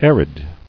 [ar·id]